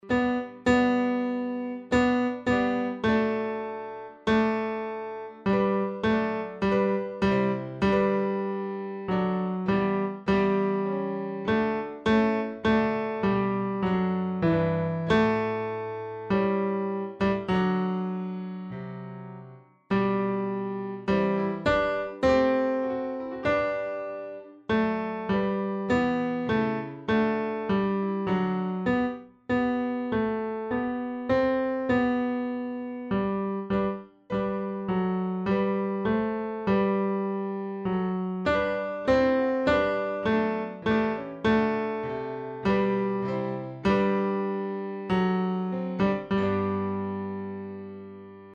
Audio-Übehilfen
TENOR (NUR CHORSATZ)
O-come-all-ye-faithfull-TENOR-NUR-CHORSATZ.mp3